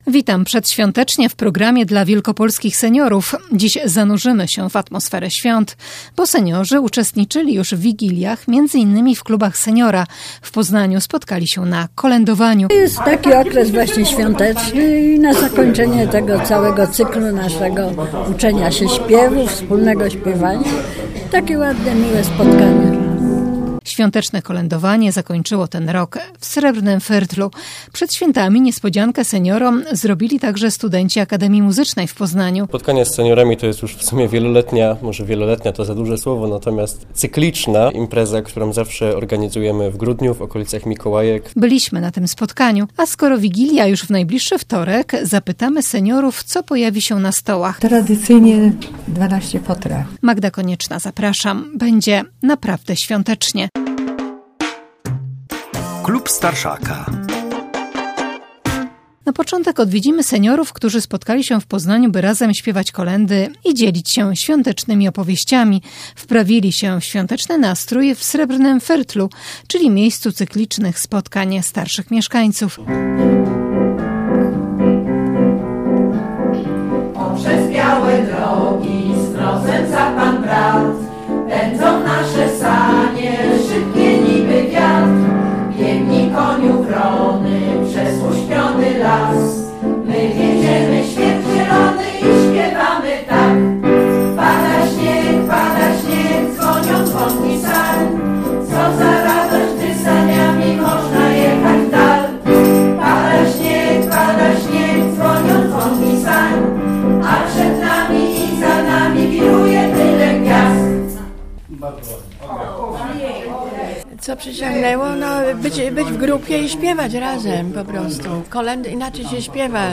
Przed świętami niespodziankę seniorom zrobili także studenci Akademii Muzycznej w Poznaniu i zaprosili ich do siebie na wspólne śpiewanie kolęd. Byliśmy na tym spotkaniu.